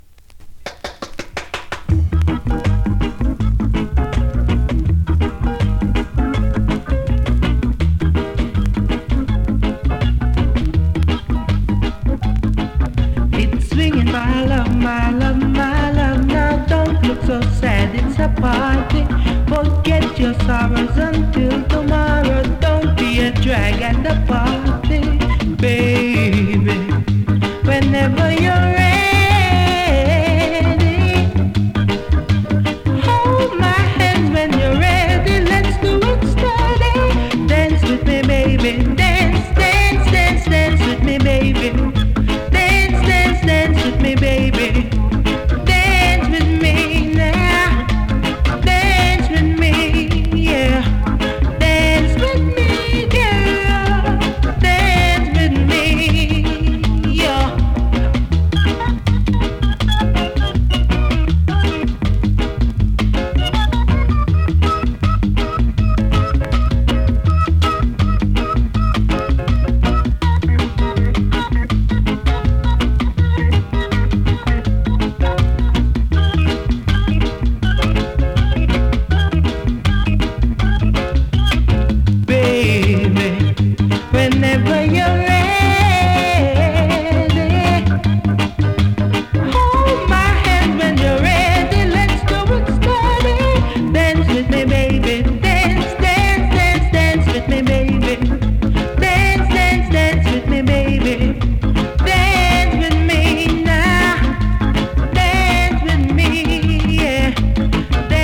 SKA〜REGGAE
スリキズ、ノイズ比較的少なめで